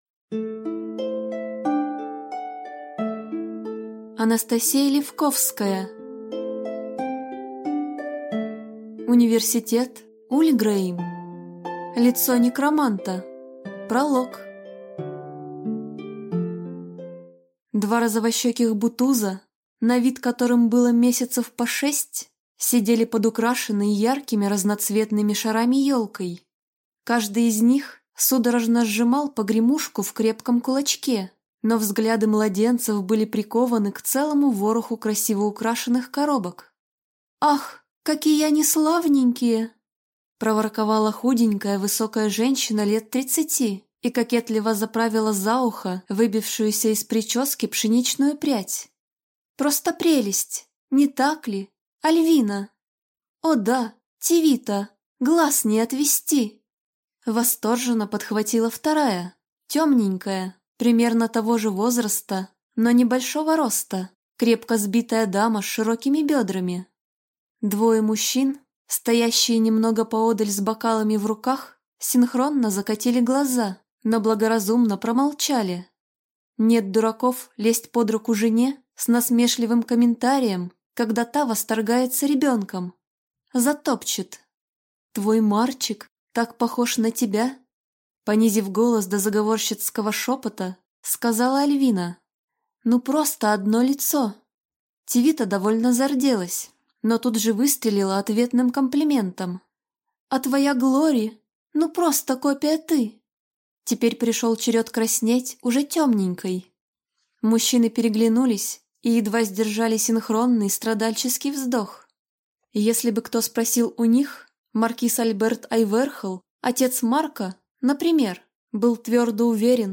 Аудиокнига Университет Ульгрейм. Лицо некроманта | Библиотека аудиокниг